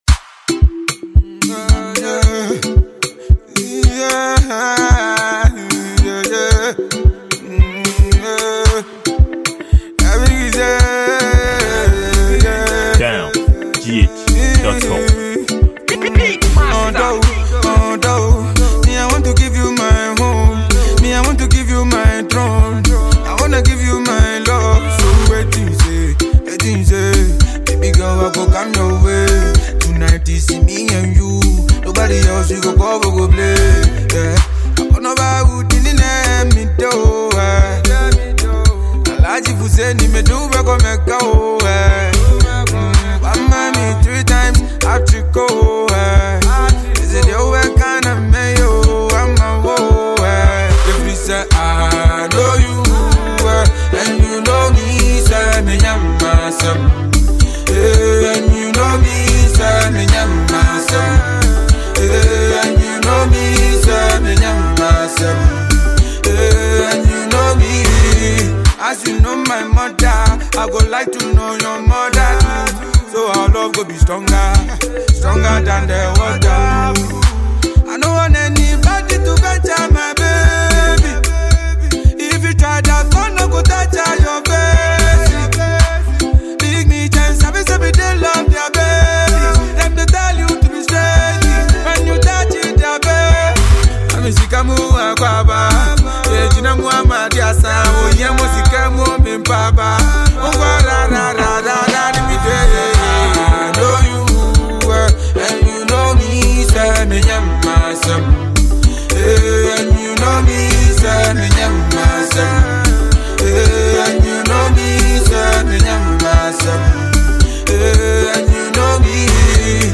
Ghanaian afrobeats highlife singer and musician